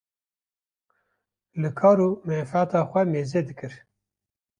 Pronounced as (IPA) /meːˈzɛ/